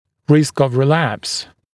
[rɪsk əv rɪ’læps][риск ов ри’лэпс]риск возникновения рецидива